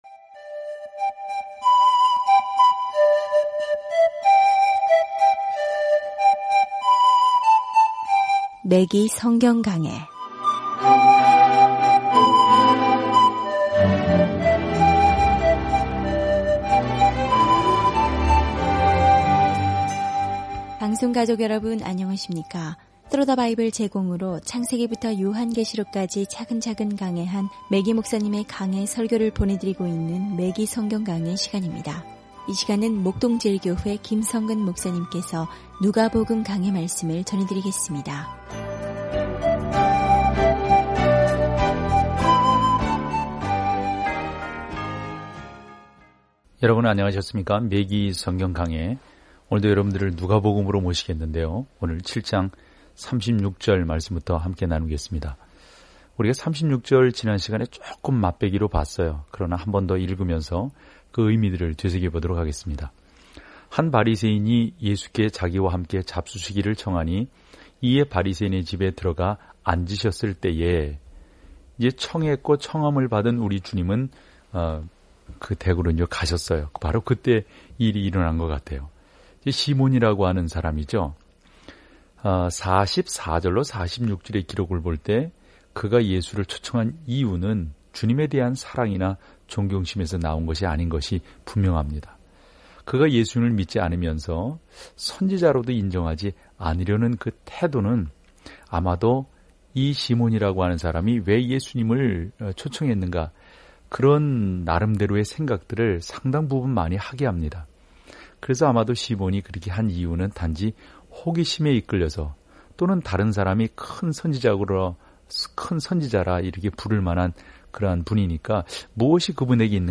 오디오 공부를 듣고 하나님의 말씀에서 선택한 구절을 읽으면서 매일 누가복음을 여행하세요.